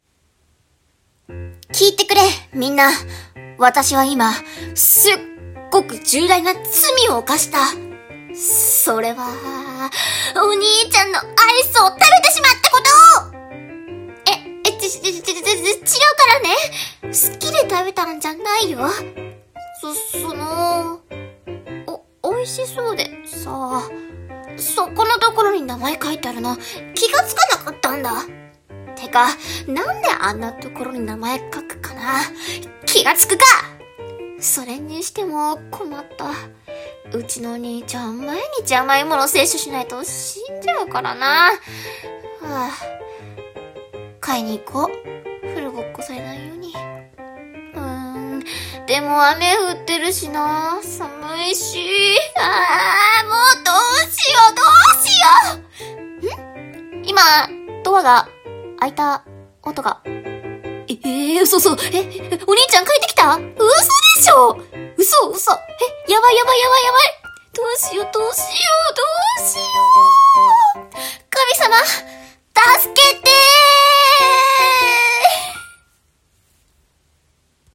【声劇】 アイス食べちゃった